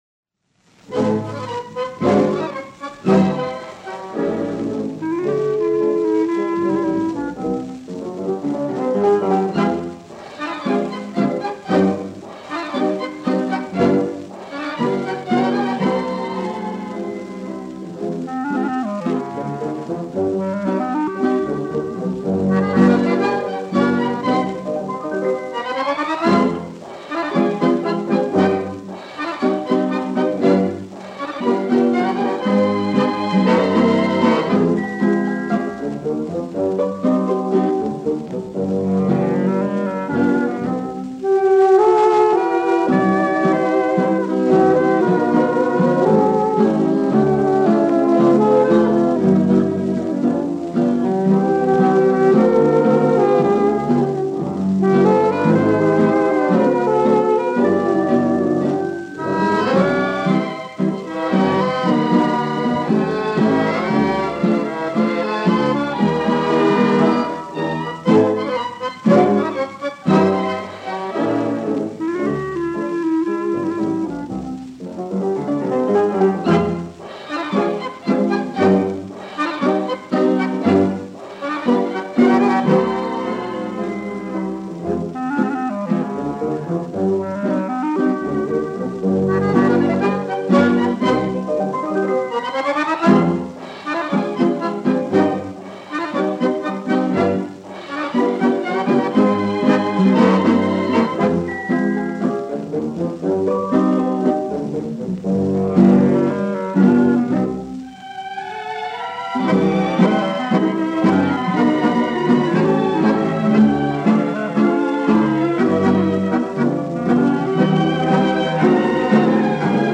Каталожная категория: Эстрадный оркестр |
Жанр: Танго
Вид аккомпанемента:    Эстрадный оркестр
Место записи:    Москва |